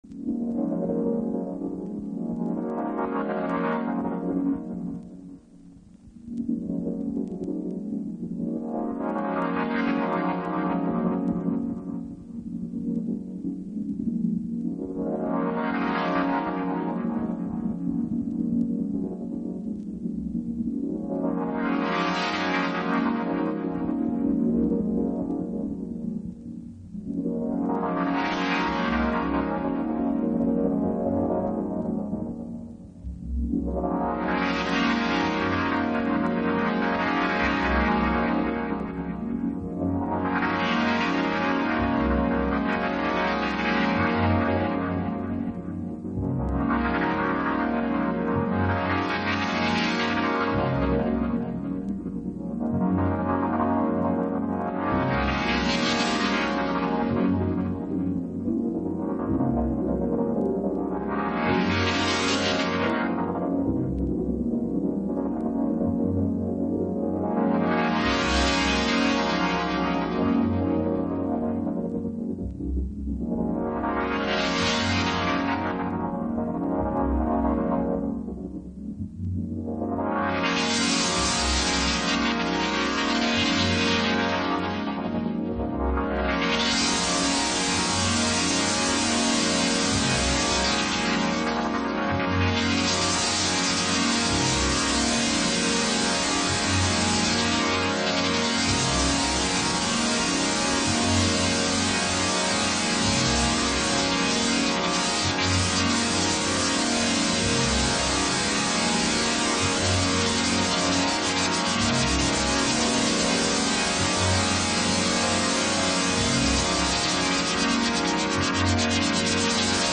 House / Techno
ダークサイドなエレクトロ。